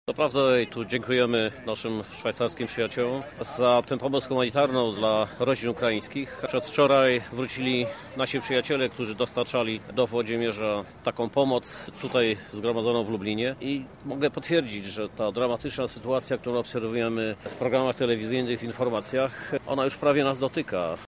Ta dramatyczna sytuacja, którą obserwujemy w mediach, też nas dotyka – mówi Krzysztof Żuk, prezydent Lublina.